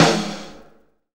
LONG SNR.wav